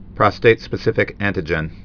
(prŏstātspĭ-sĭfĭk)